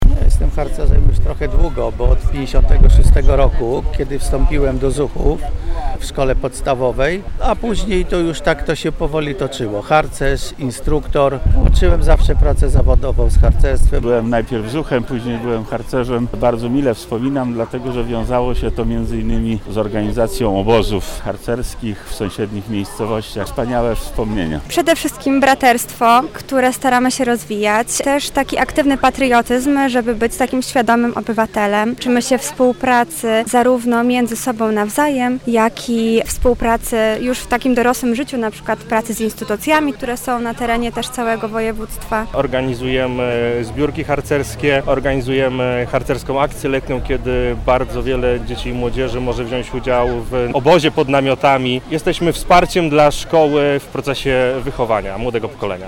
Ze starszymi i młodszymi harcerzami rozmawiała nasza reporterka:
relacja